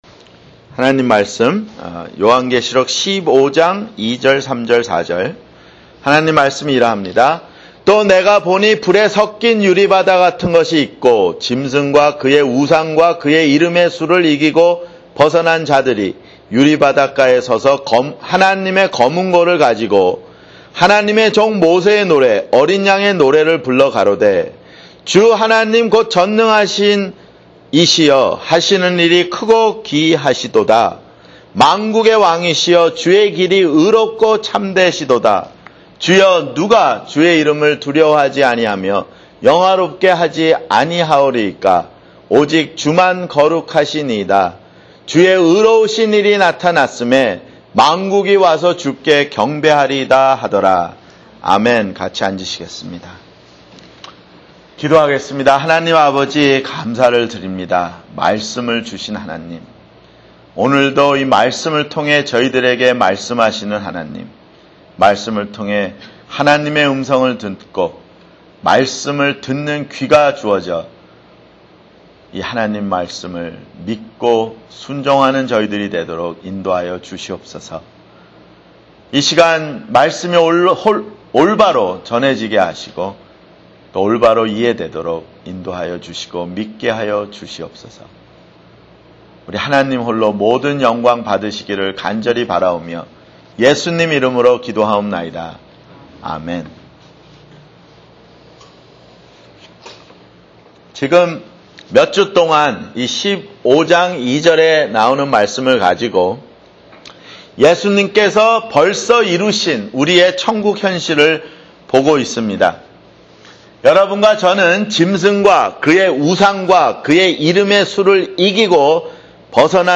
[주일설교] 요한계시록 (57)